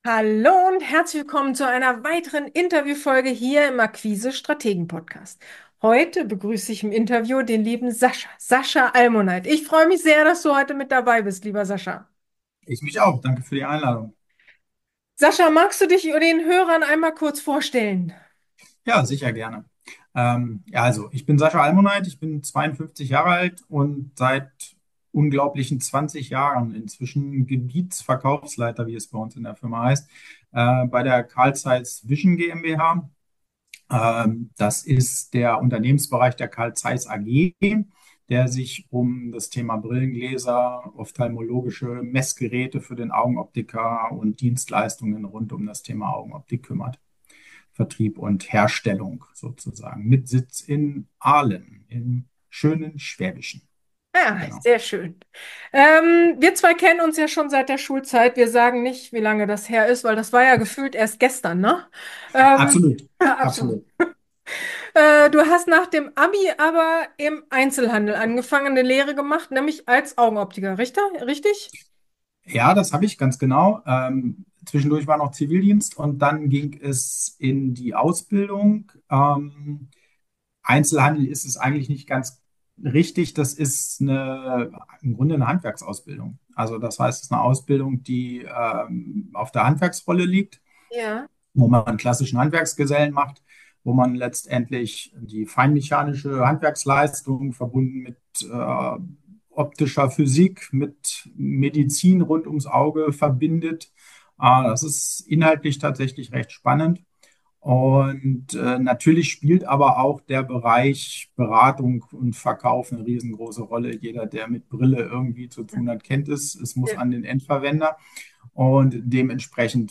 Vertrieb im Wandel | Interview